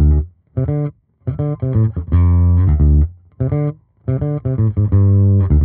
Index of /musicradar/dusty-funk-samples/Bass/85bpm
DF_JaBass_85-D.wav